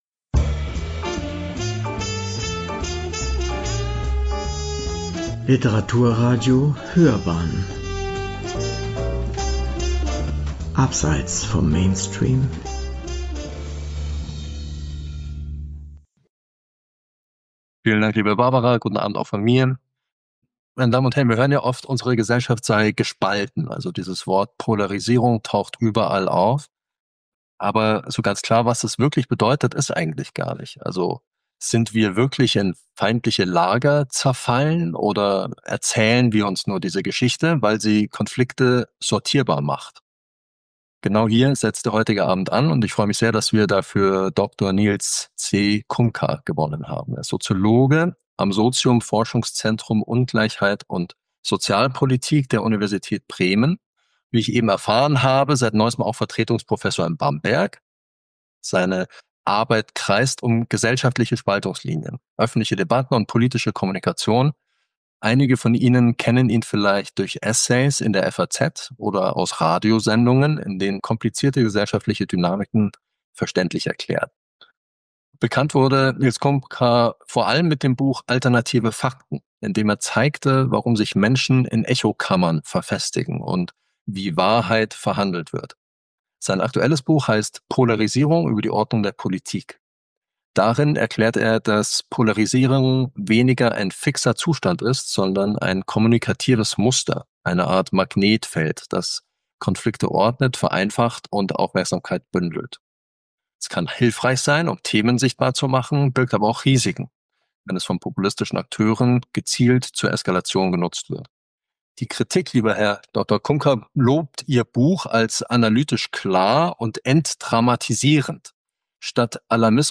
in der ev. Stadtakademie München